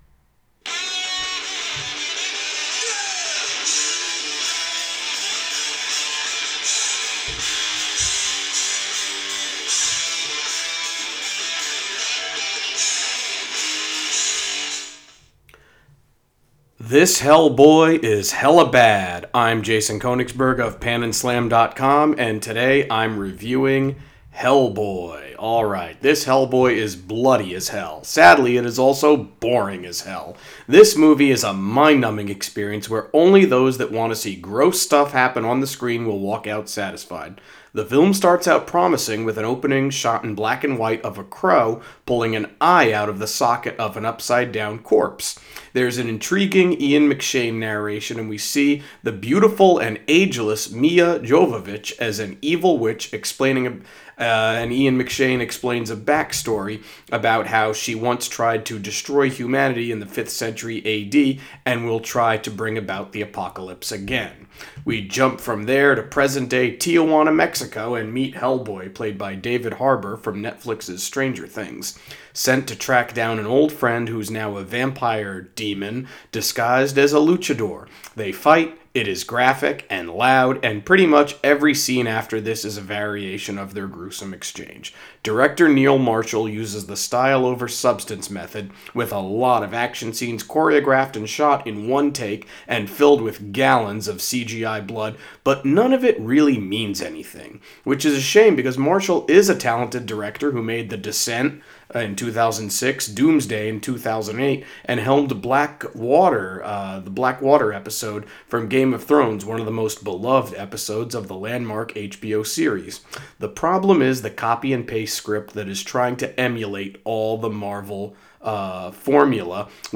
Movie Review: Hellboy